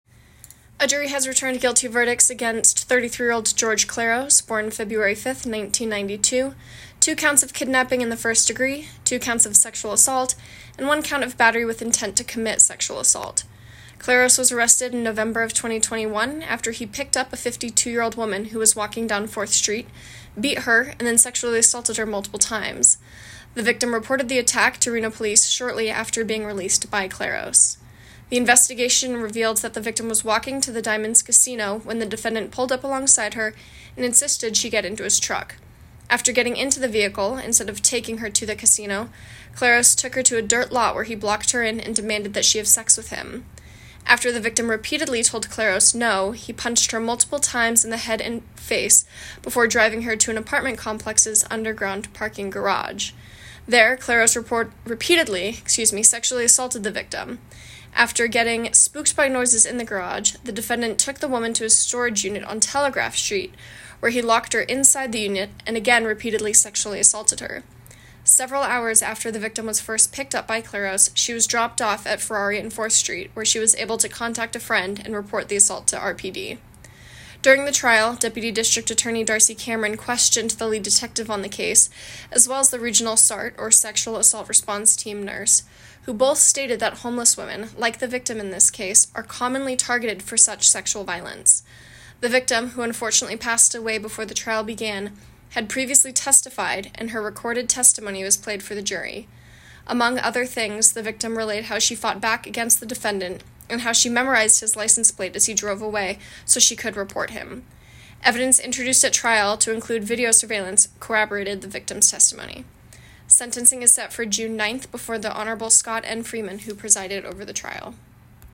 A recording of the press release for media can be heard below.